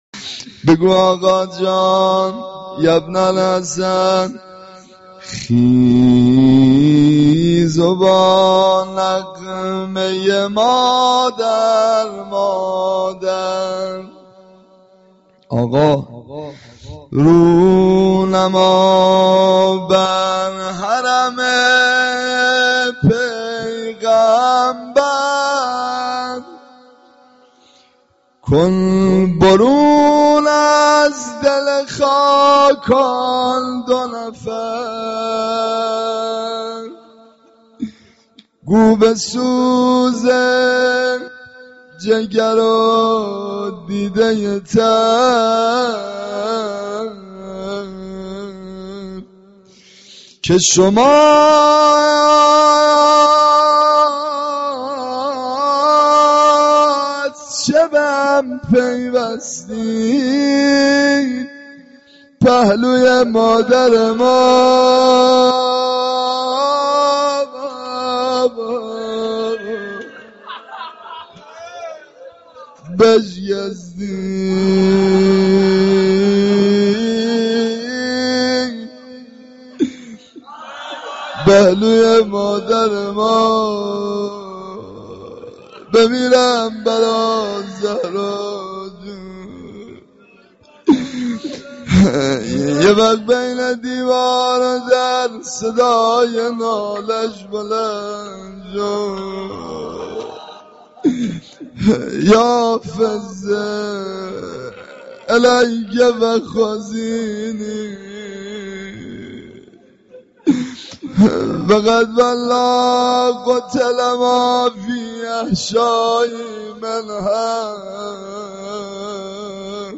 روضه حضرت زهرا(س)